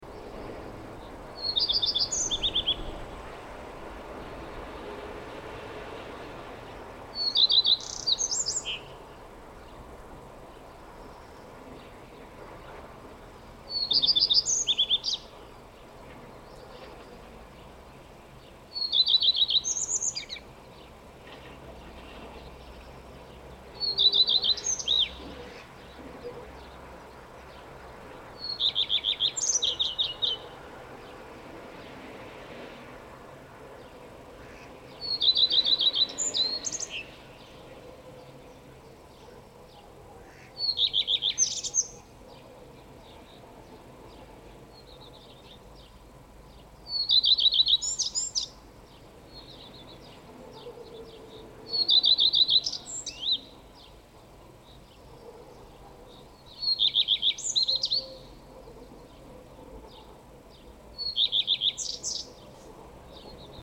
U� p�r dn� mi na zahrad� je�� n�jak� pt�k, d�l� n�co jako "�hahaha", fakt jak �eht�n�. Je mo��, �e mu jin� hned odpov�d�, ale jde mi prim�rn� o toho, co "�eht�".Sed� n�kde ve v�tv�ch o�echu, je plachej, jak otev�u okno, tak odlet�, ale hed zase p��l�tne.